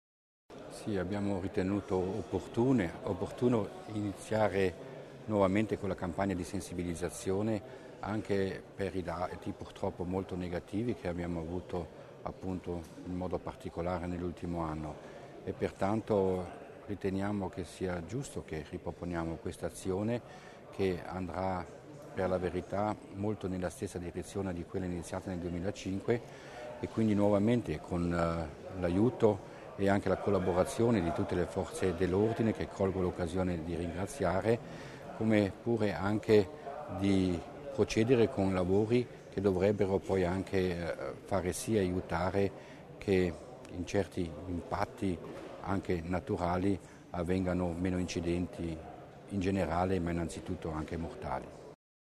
L'Assessore Mussner spiega i dettagli della campagna No Credit